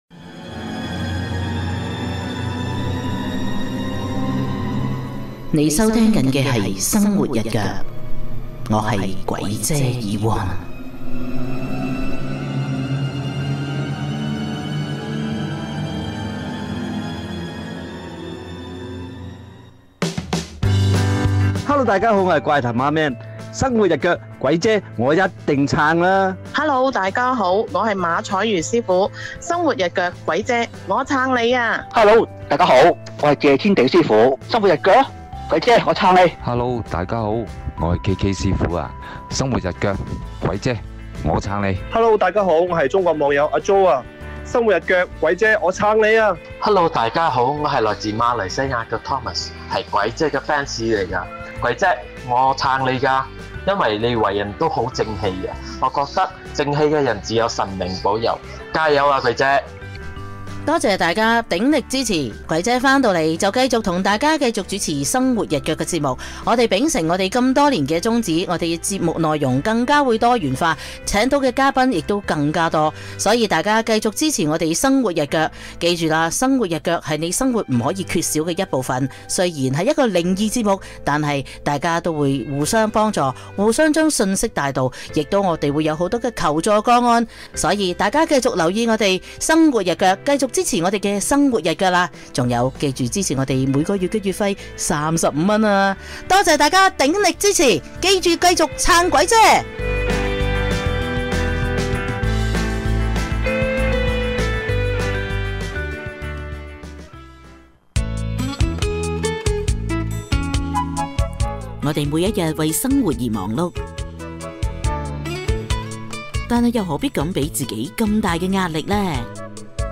同片突發錄到怪聲